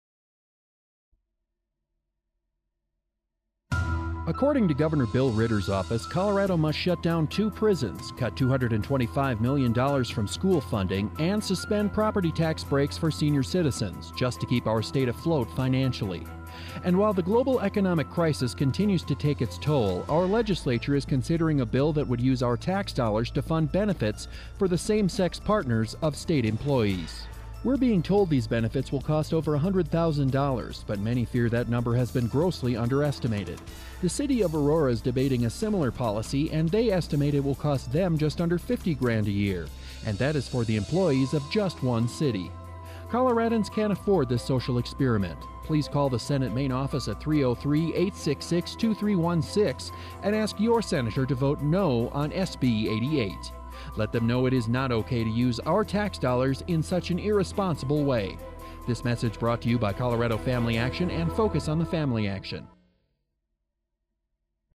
Colorado Springs-based Focus on the Family is taking its inevitable opposition to Senate Bill 88 to Colorado radio this week. SB-88, which would provide health benefits to the same-sex partners of state employees, is being hit with much less sensationalist spin than recent Focus state legislative efforts–compare this new, relatively dry ad (click below) to last year’s hysterical fearmongering over SB08-200, the dreaded “bathroom bill.”